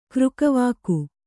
♪ křkavāku